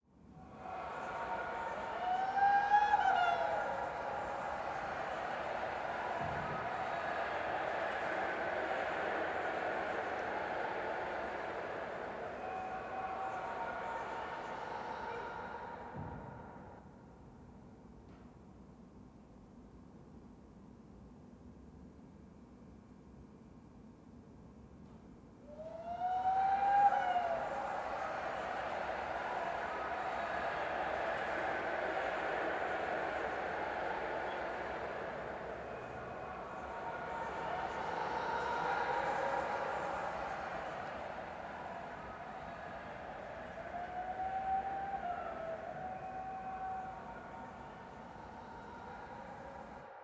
sfx_bg_mix.opus